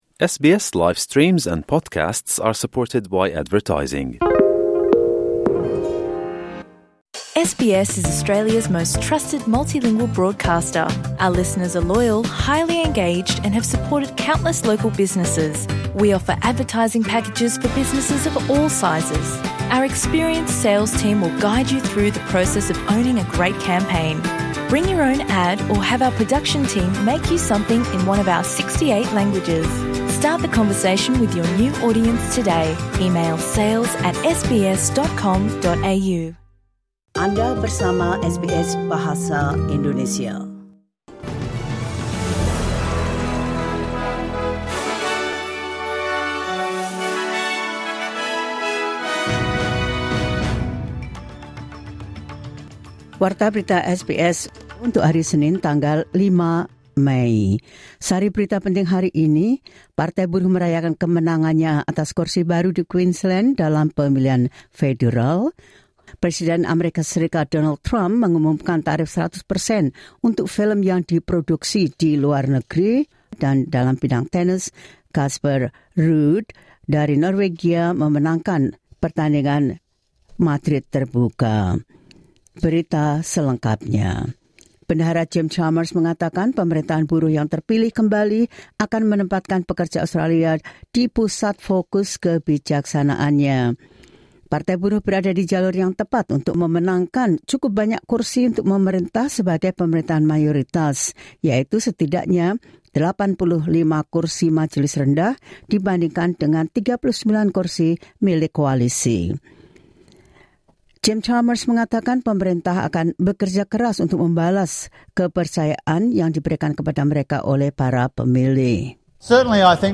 Latest News SBS Audio Indonesian Program – 05 MAY 2025.